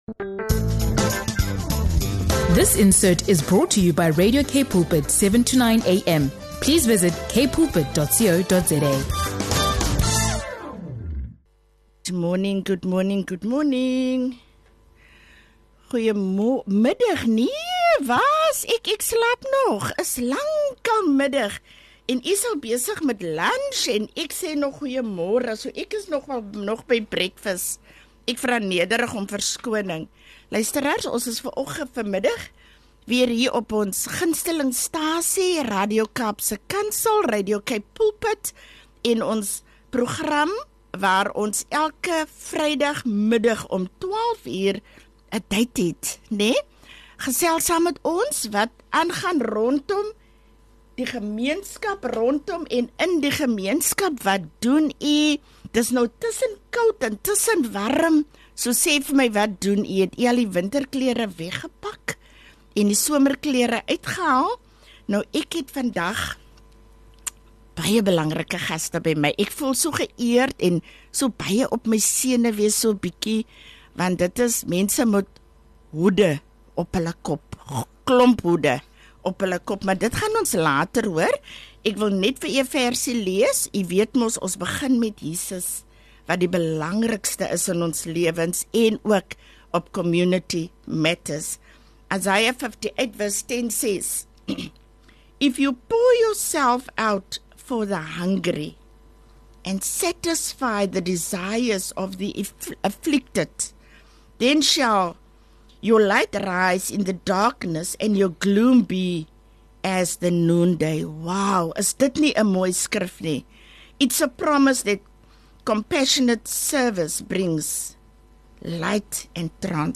Join us for a heartwarming and inspiring episode of our community broadcast!